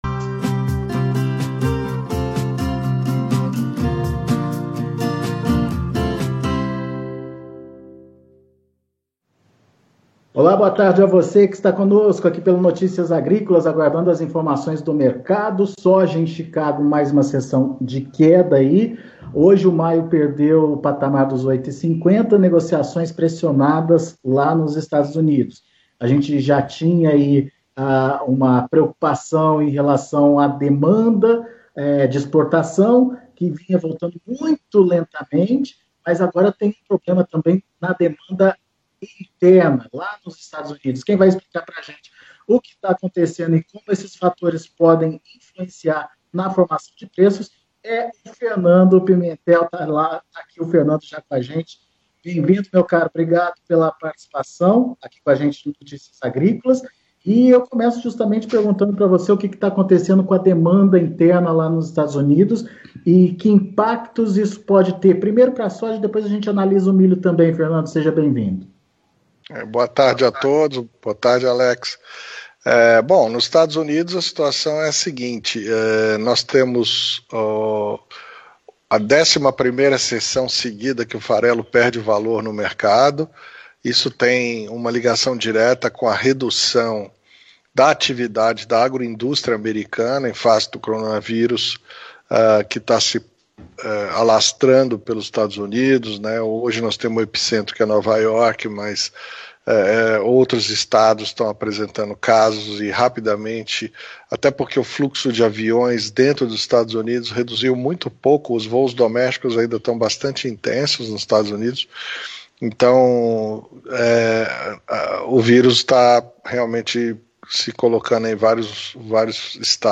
Fechamento de Mercado da Soja - Entrevista